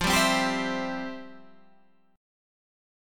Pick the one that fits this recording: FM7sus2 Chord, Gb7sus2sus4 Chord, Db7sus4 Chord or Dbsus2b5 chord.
FM7sus2 Chord